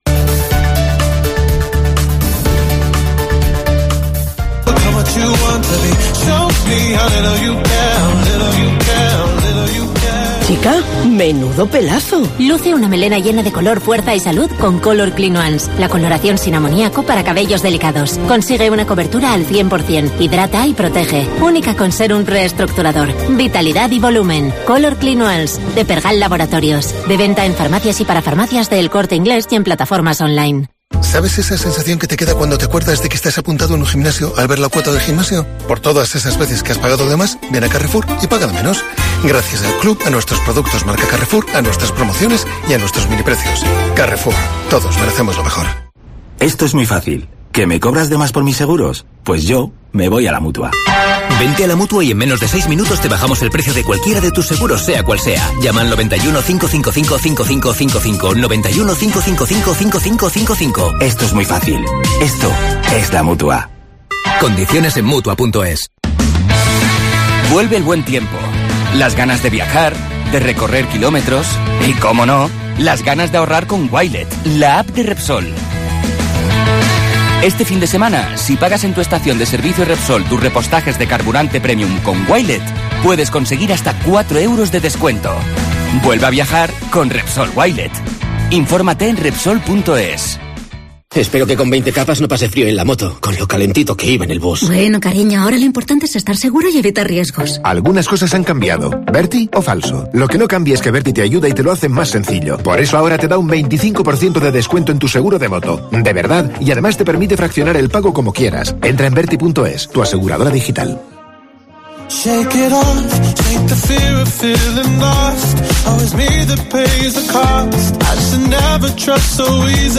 Los trabajadores de Roldán de Ponferrada deciden desconvocar la huelga por la prima de producción (Entrevista